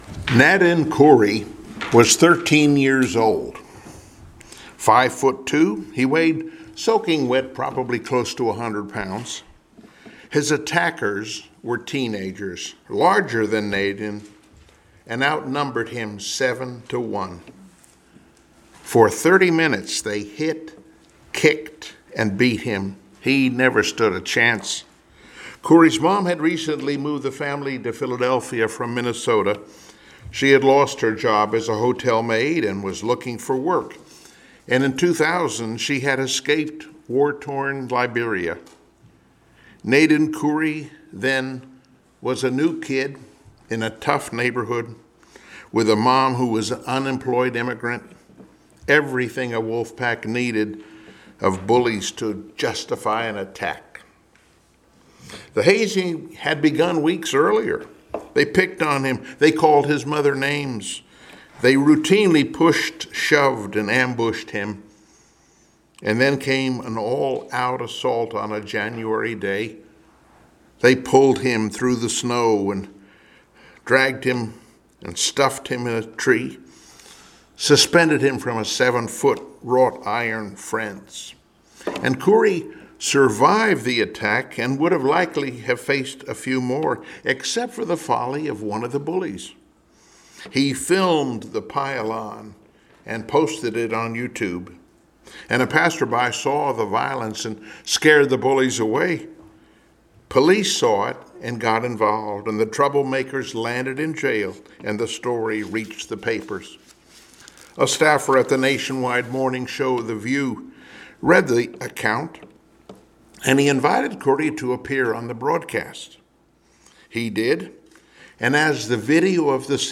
Passage: Joshua 23 Service Type: Sunday Morning Worship Topics